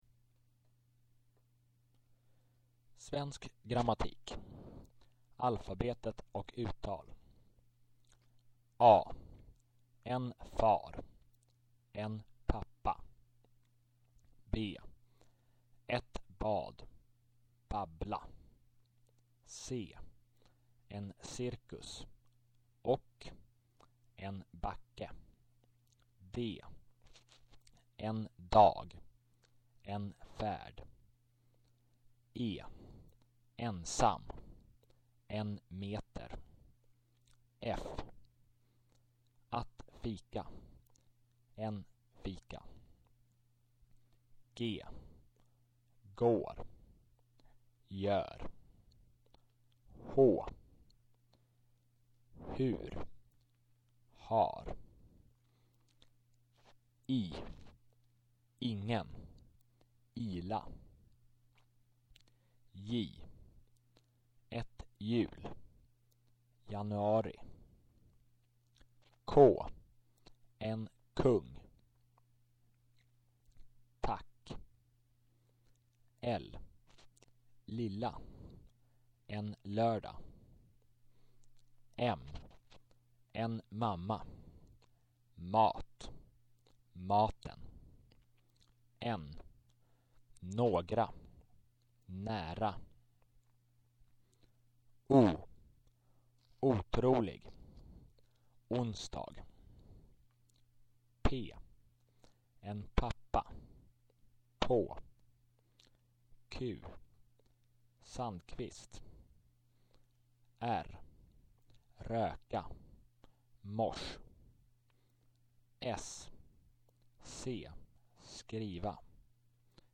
Sound-file: swedish_grammar.mp3 (8 MByte, first try, not so good quality)